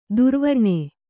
♪ durvarṇe